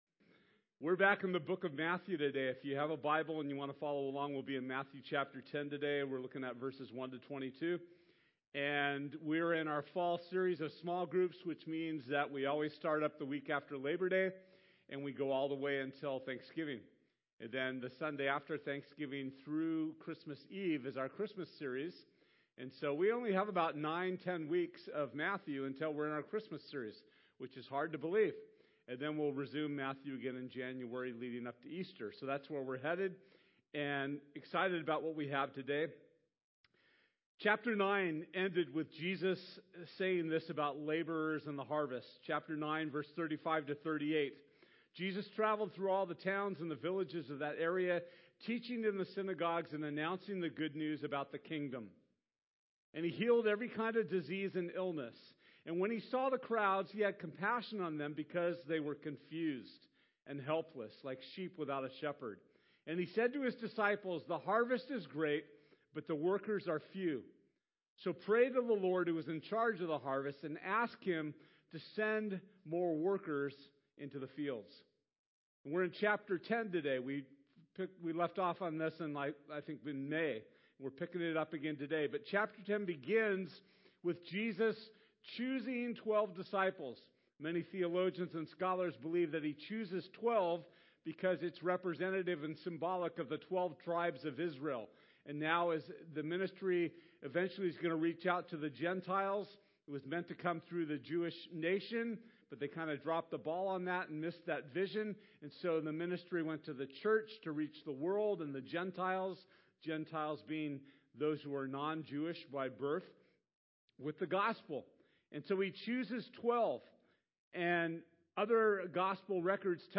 Matthew 10:1-22 Service Type: Sunday This Sunday we’ll be back in our study of the Gospel of Matthew.